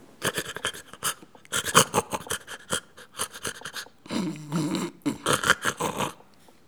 Les sons ont été découpés en morceaux exploitables. 2017-04-10 17:58:57 +02:00 1.1 MiB Raw Permalink History
reniflement-animal_01.wav